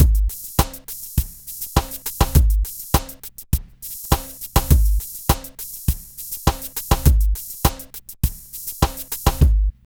Ala Brzl 1 Fnky Drmz.wav